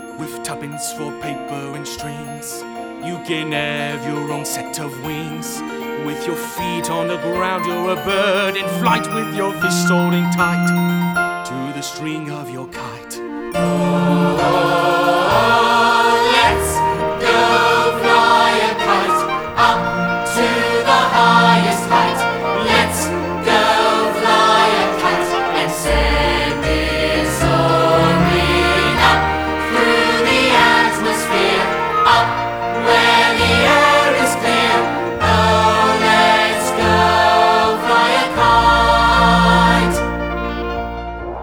AUDITION SELECTIONS WITH VOCALS - USE FOR PRACTICE